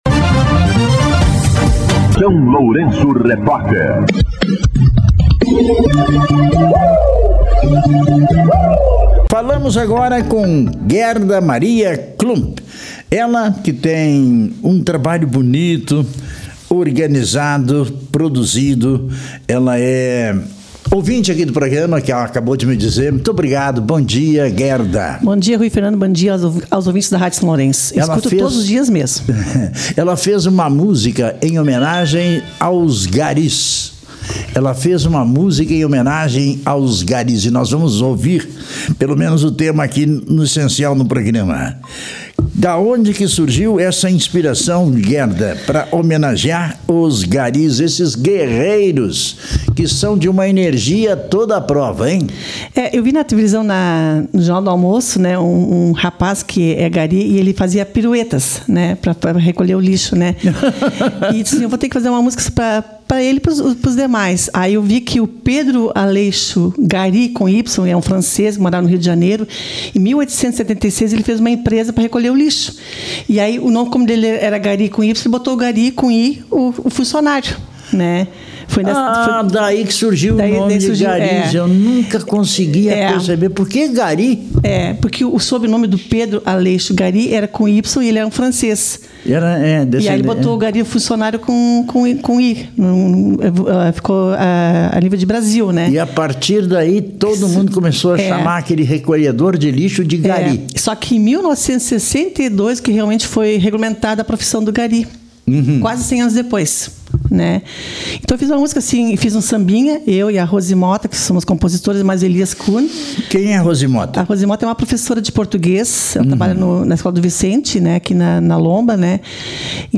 no refrão faz voz/dupla e um contralto. No ritmo ‘Samba’